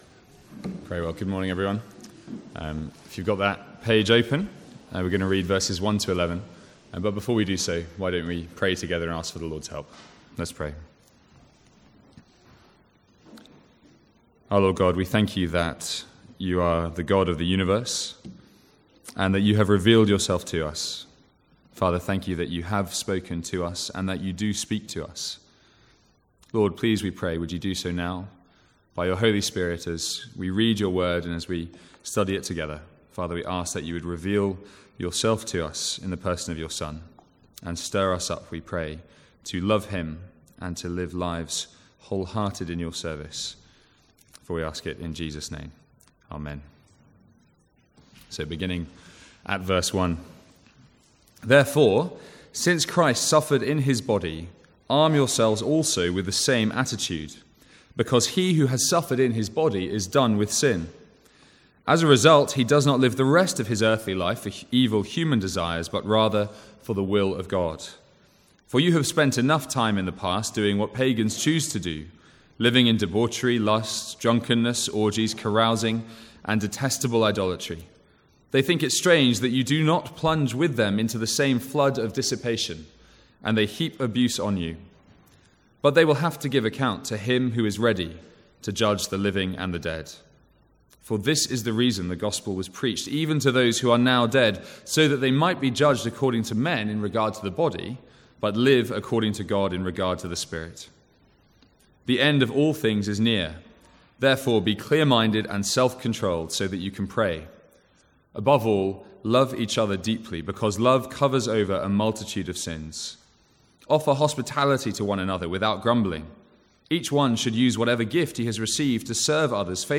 Sermons | St Andrews Free Church
From the Sunday morning series in 1 Peter.